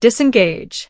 carrot / selfdrive / assets / sounds_eng / audio_disengage.wav
audio_disengage.wav